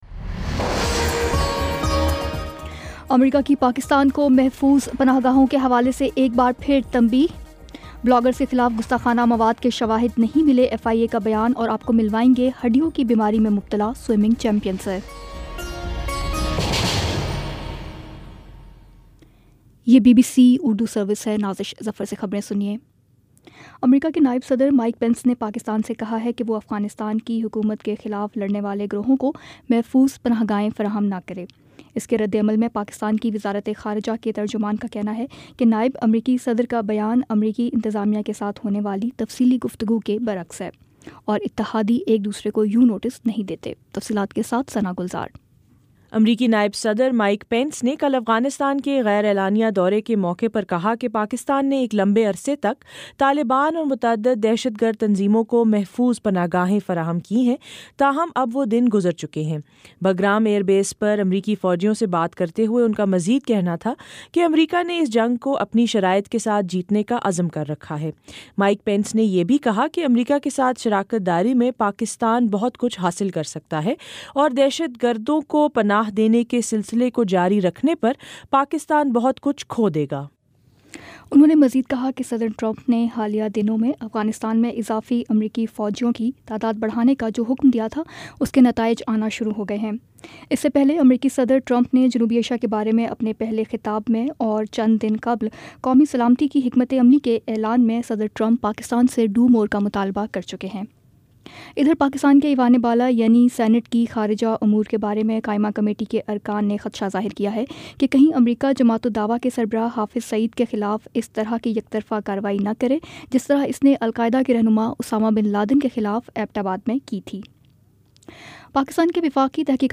دسمبر 22 : شام پانچ بجے کا نیوز بُلیٹن